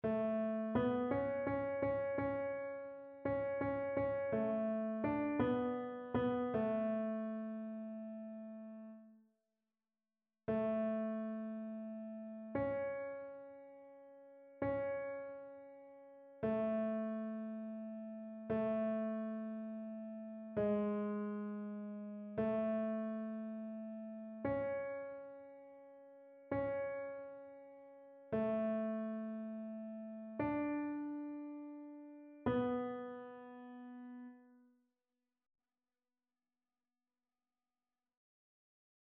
Ténor
annee-b-temps-ordinaire-32e-dimanche-psaume-145-tenor.mp3